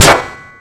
Cool metal clang sounds
metal sounds since it won’t let me public them: